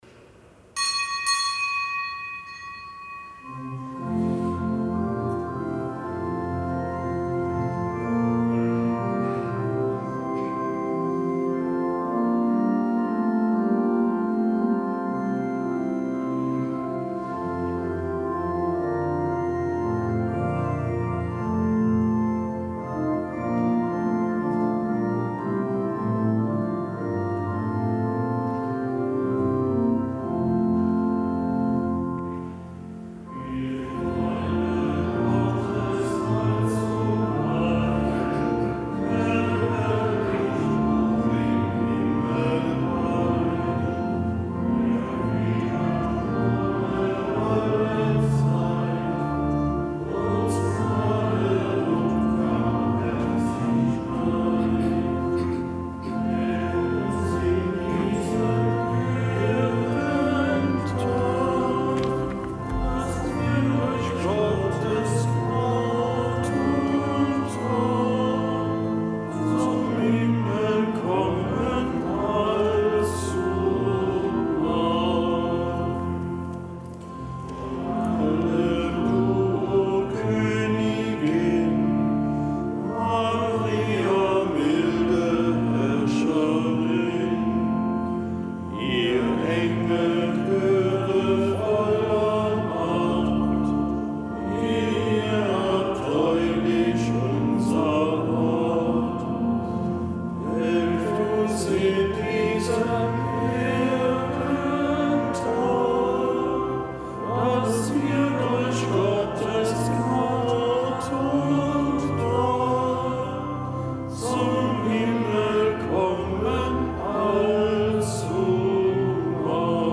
Kapitelsamt am Fest Heiligen Cyrill
Kapitelsmesse aus dem Kölner Dom am Fest Heiligen Cyrill, Bischof und Glaubensbote bei den Slawen und Schutzpatron Europas.